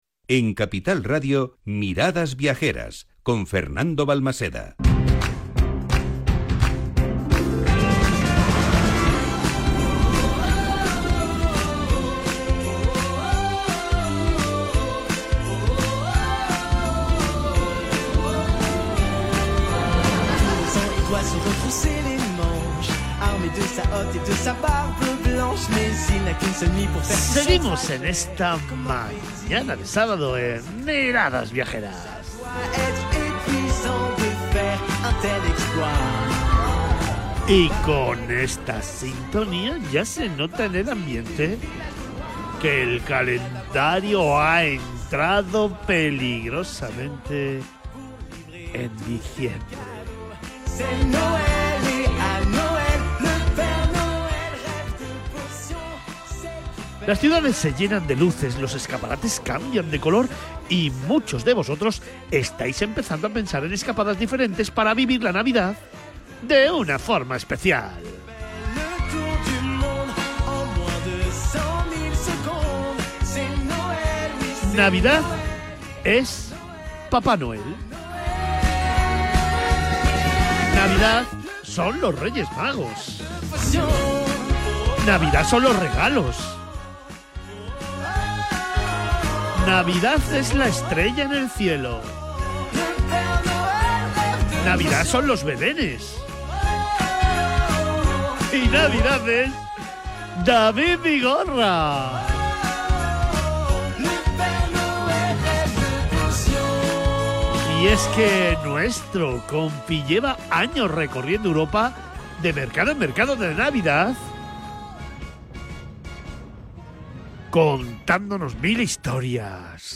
asterix-navidad-radio.mp3